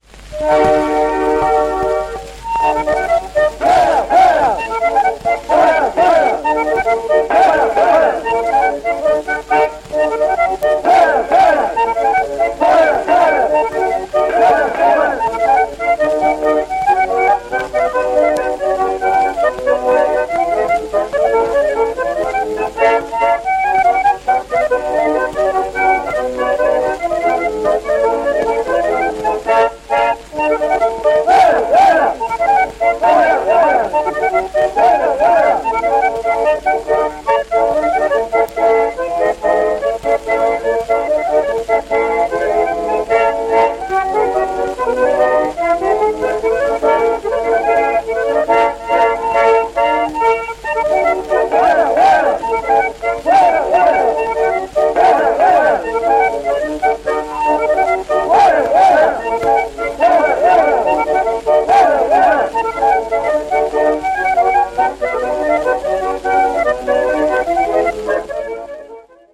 Эта грамзапись была сделана в Москве летом 1910 года: